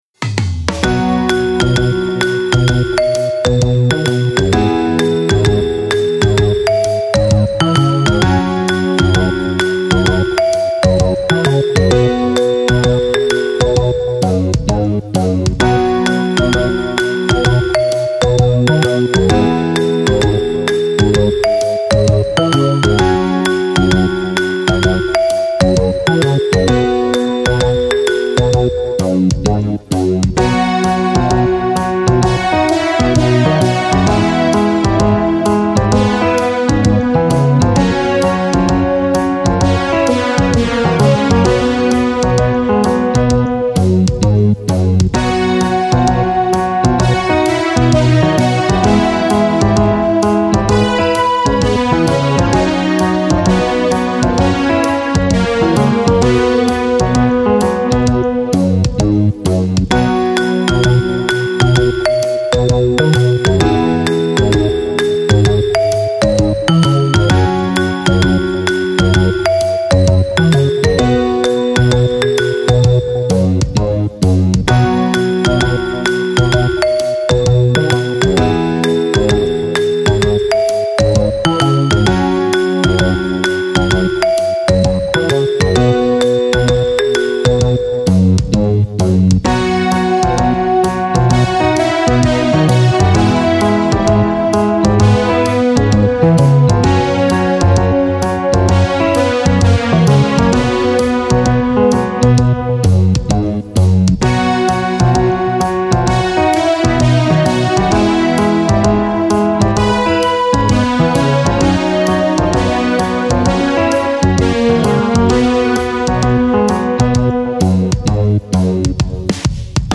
【用途/イメージ】　アニメ　ナレーション　穏やか　陽気　ゆったり